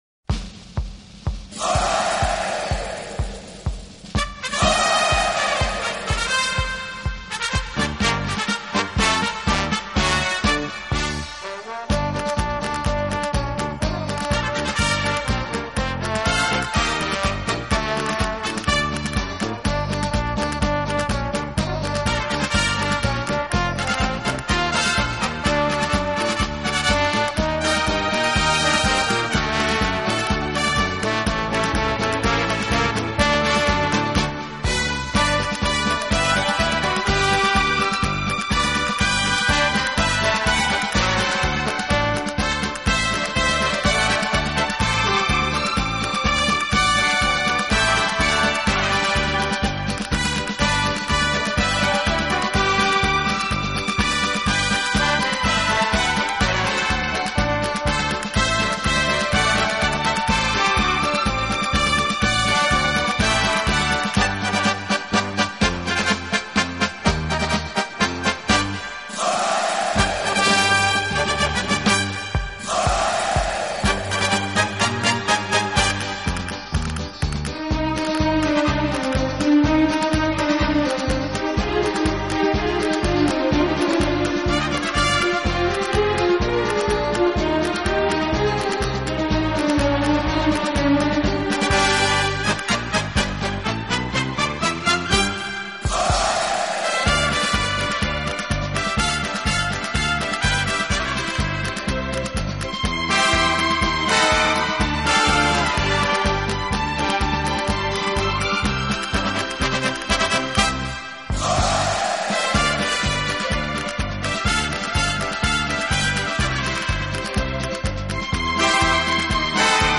顶级轻音乐
乐常透露出欢乐、愉快和乐观主义的情感，有一种不可抗拒的吸引力和动人心弦的